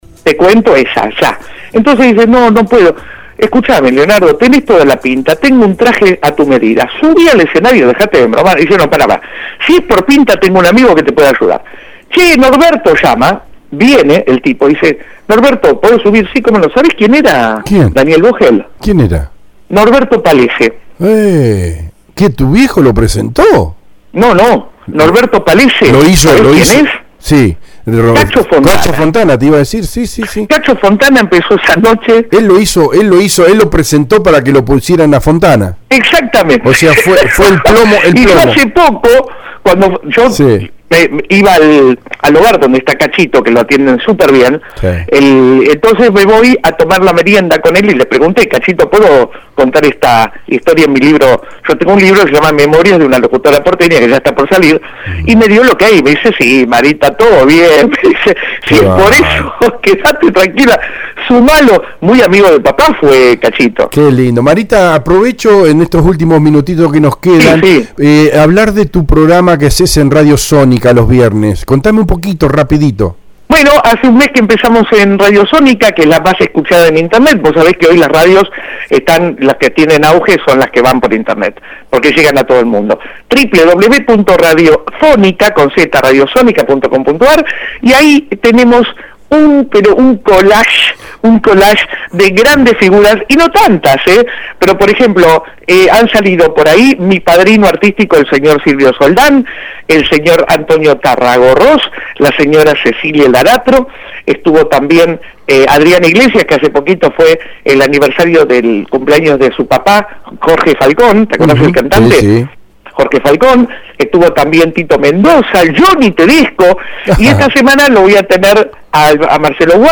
LOS IMPERDIBLES AUDIOS DE LA ENTREVISTA